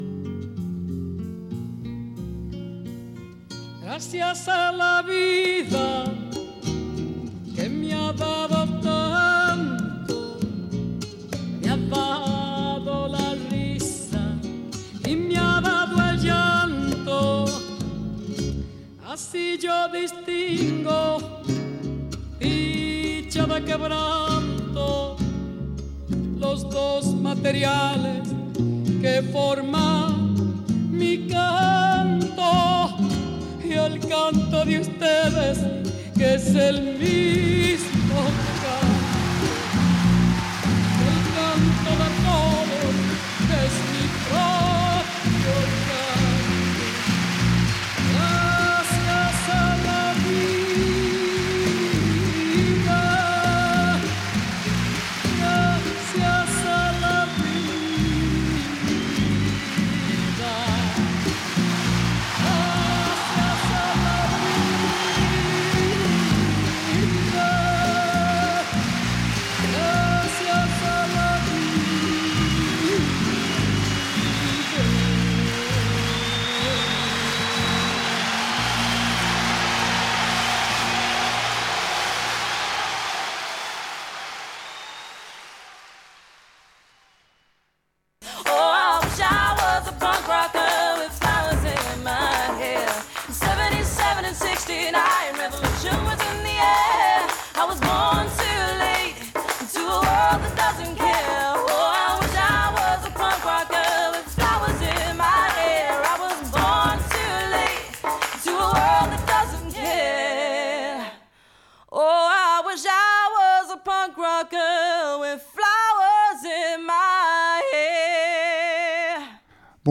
I Wish I was a punkrocker, réalisée en direct un jeudi sur deux de 19h à 20h